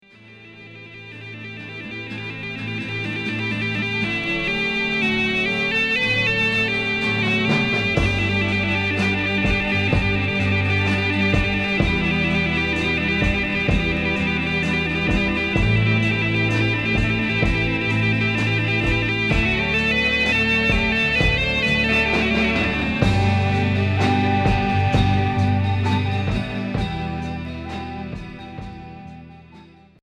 Folk électrique